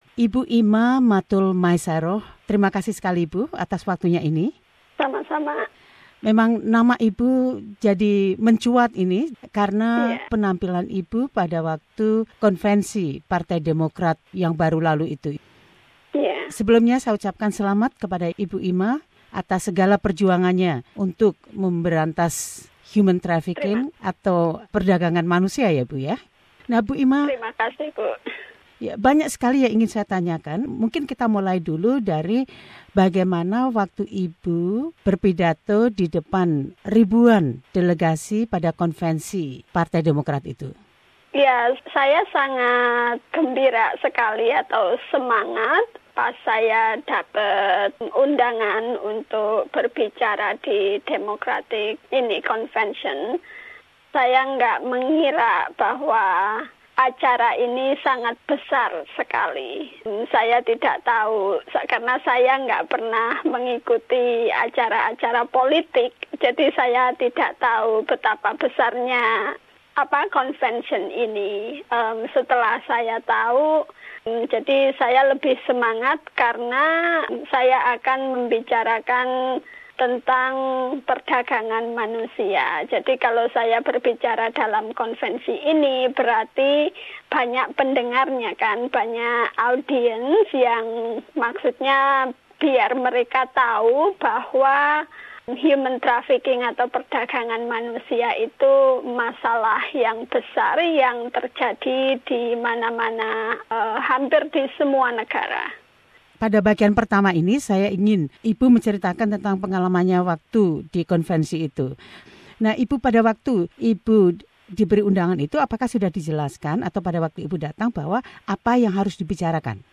wawancara